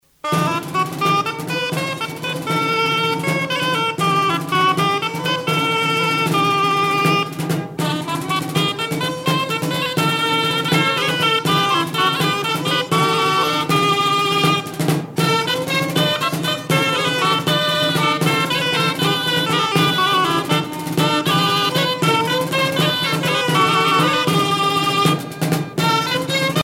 Castillon-en-Couserans
danse : valse
les hautbois